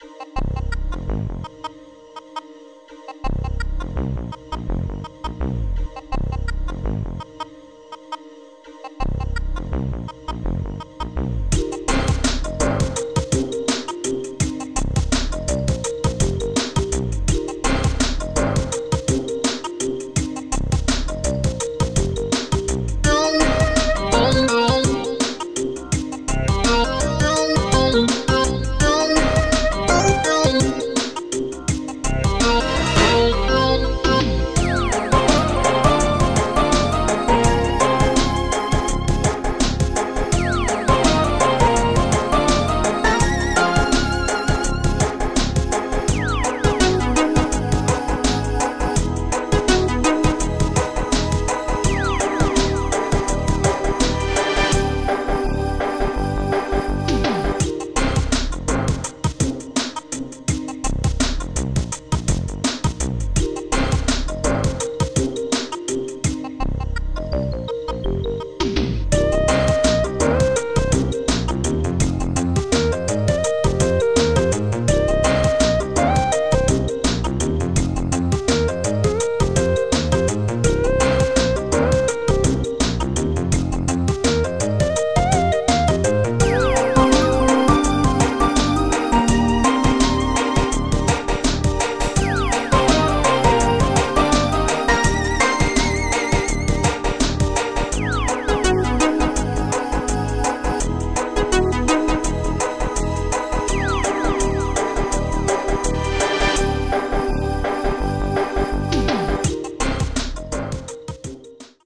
the lead at the beginning of this is a sample called TheEgg. it is possibly the greatest sample ever.